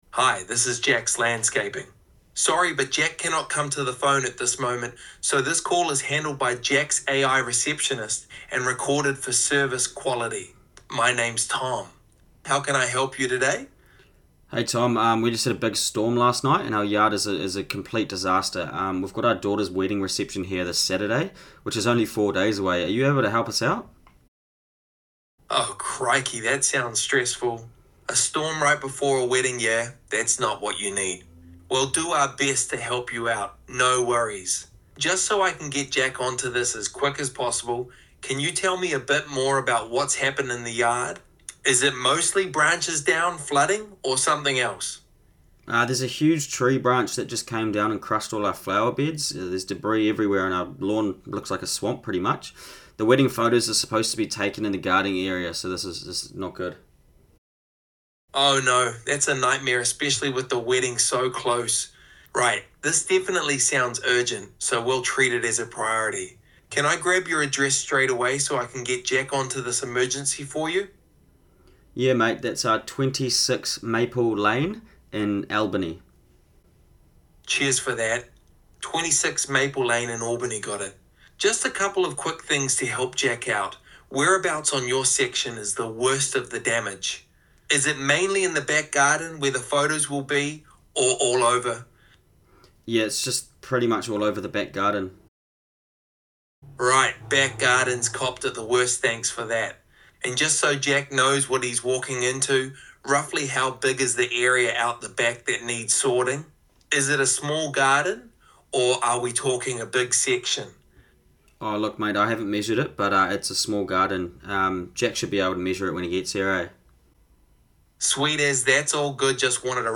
Sounds Completely Human
🎧 Customer inquiry – Urgent Backyard Debris Clearing